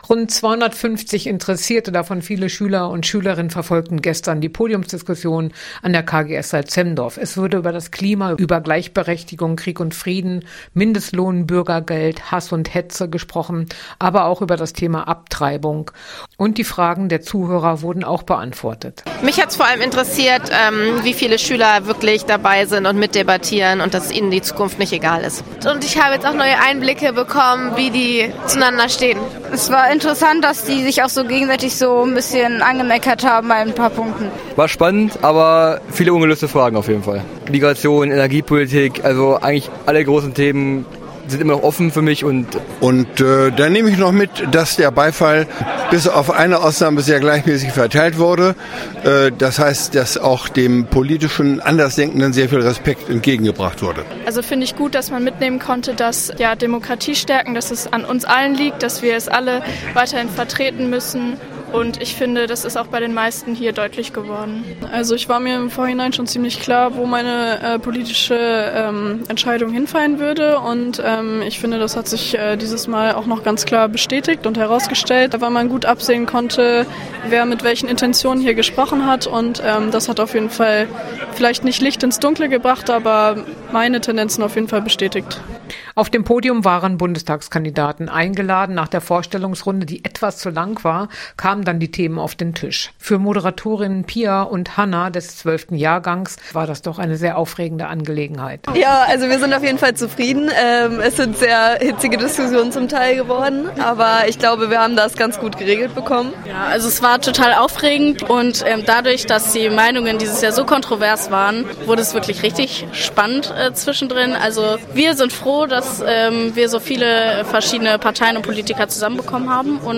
Salzhemmendorf: Podiumsdiskussion mit den heimischen Bundestagskandidaten
salzhemmendorf-podiumsdiskussion-mit-den-heimischen-bundestagskandidaten.mp3